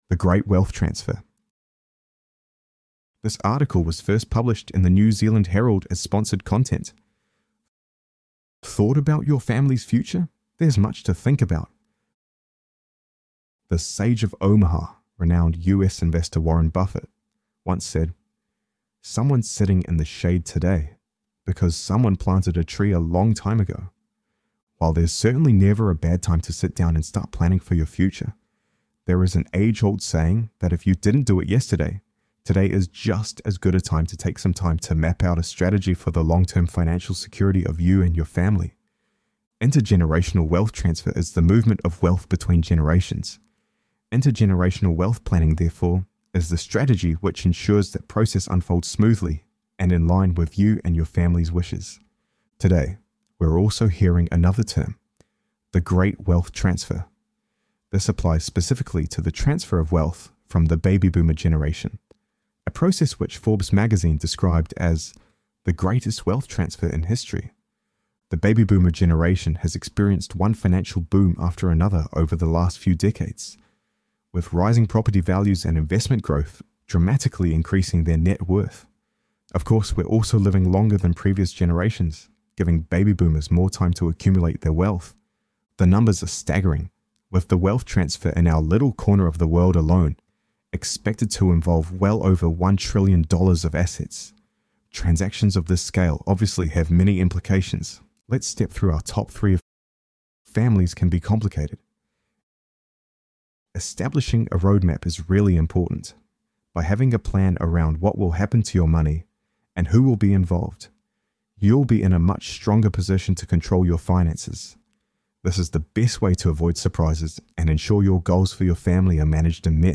This content features an AI-generated voice for narration purposes.
ElevenLabs_Retirement_-_The_great_wealth_transfer.mp3